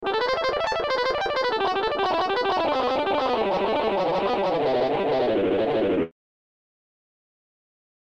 Legato-Tapping+Exercise.mp3